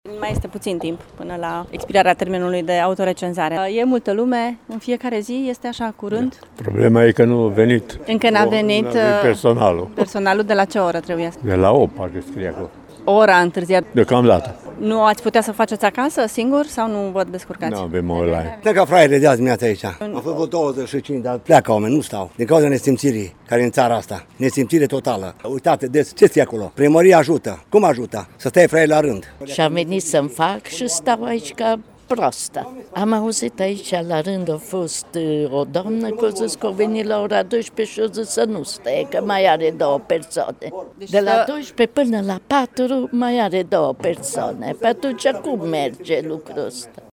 La centrul de recenzare din Tudor, de pe strada Cutezanței, zeci de oameni stăteau azi-dimineață la rând, și nu era nimeni să-i ajute.
Lipsa recenzorilor nu e una nouă, aflăm de la târgumureșenii supărați: